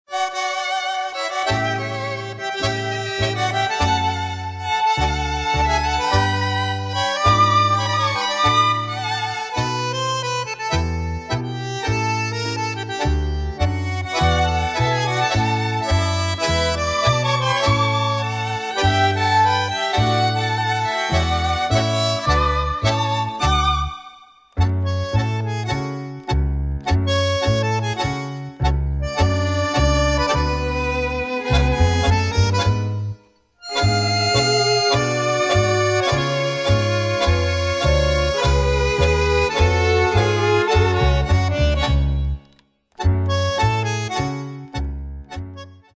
Sonidos de bases  musicales de Tangos